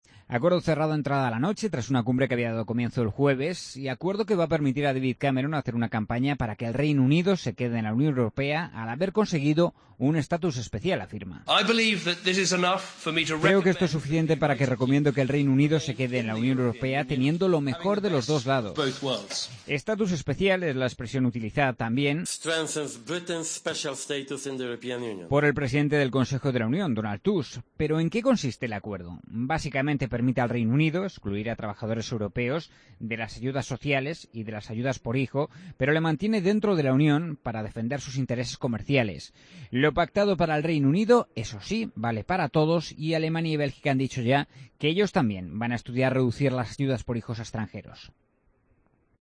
crónica del corresponsal